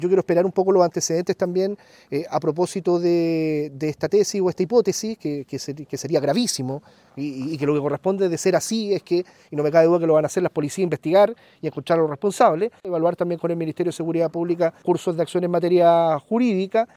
Sobre lo anterior, el delegado presidencial regional para Bío Bío, Eduardo Pacheco, indicó que están a la espera de lo que arroje la investigación y que, en caso de comprobarse el accionar de terceros, evaluarán la presentación de una querella.